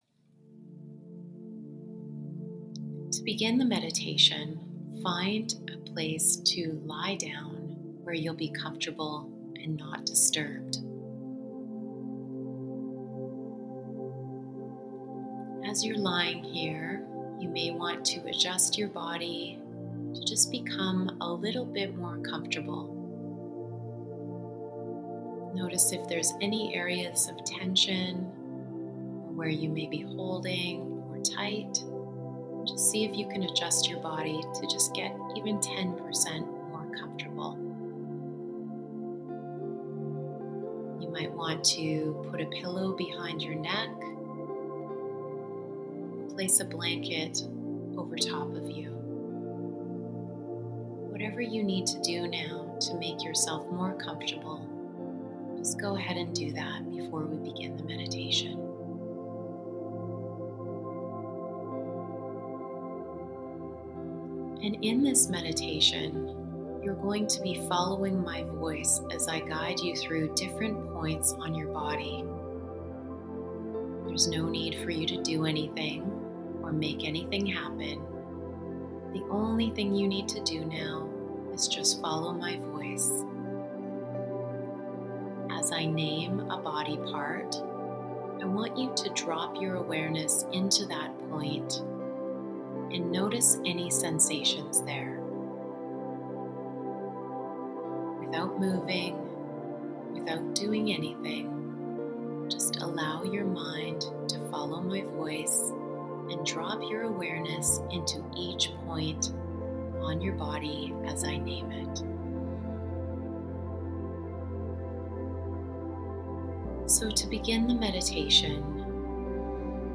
A self-guided Visualization for you to connect with your future self and share with your present-day self all of your insights and your wisdom.